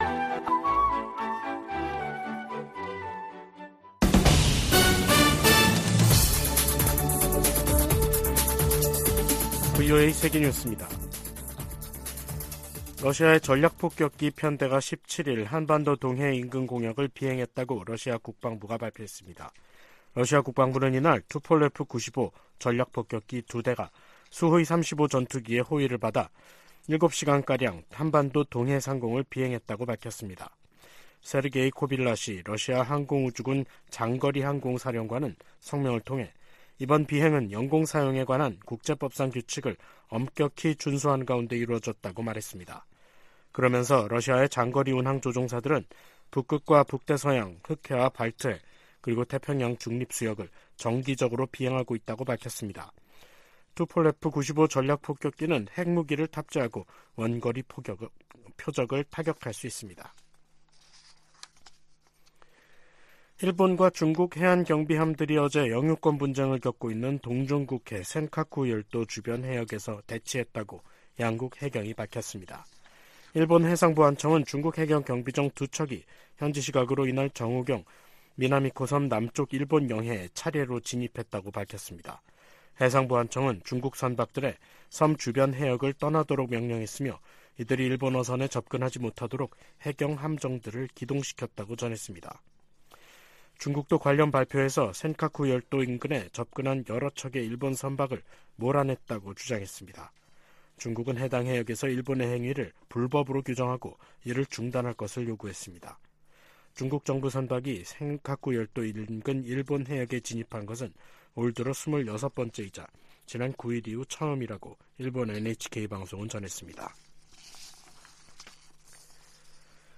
VOA 한국어 간판 뉴스 프로그램 '뉴스 투데이', 2023년 10월 17일 3부 방송입니다. 북한과 러시아 간 정상회담에 이어 러시아 외무장관의 방북 계획이 발표되면서 양측 관계가 한층 긴밀해지는 양상입니다. 북한 라진항에서 러시아로 무기를 운송한 화물선이 미국 정부의 제재를 받고 있는 선박으로 나타났습니다. 북한이 미국 본토 미사일 방어망을 무력화시킬 만큼 많은 핵 탑재 대륙간 탄도미사일 배치에 속도를 내고 있다는 미 의회 산하 기구 평가가 나왔습니다.